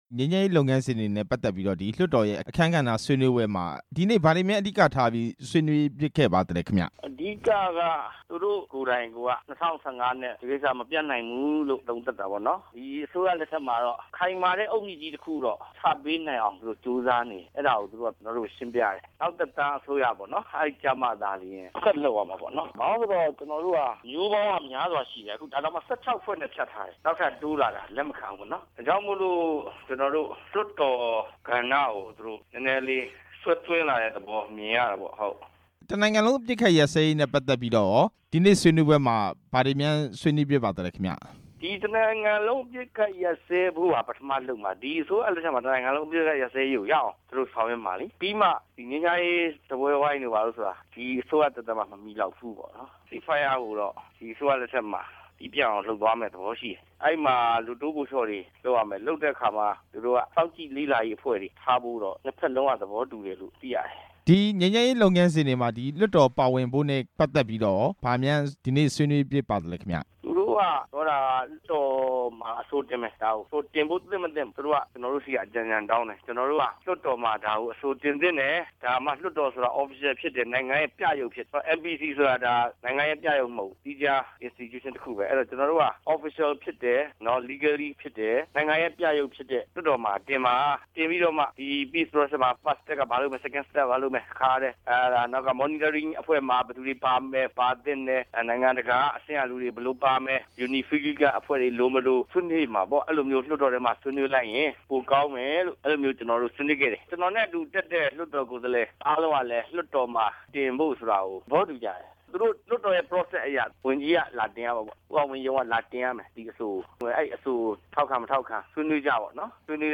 အမျိုးသားလွှတ်တော် ကိုယ်စားလှယ် ဦးလှဆွေနဲ့ မေးမြန်းချက်